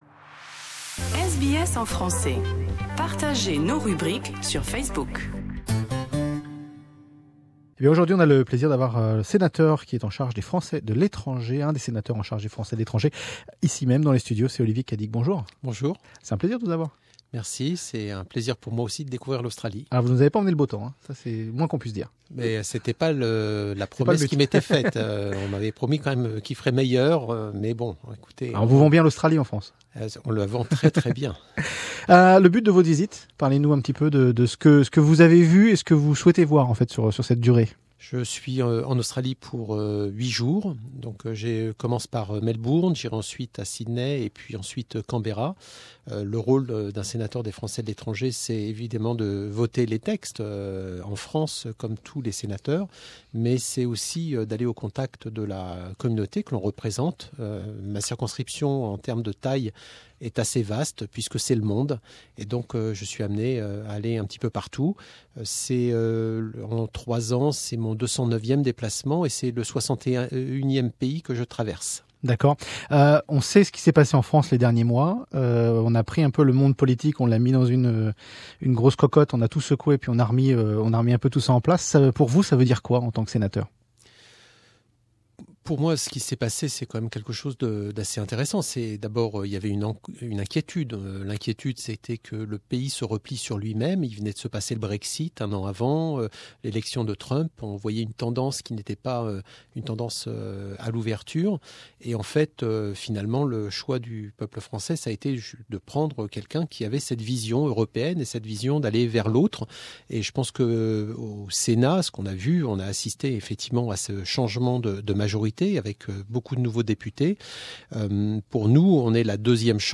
Rencontre avec le sénateur des Français de l'étranger Olivier Cadic .. dans nos studios à Melbourne.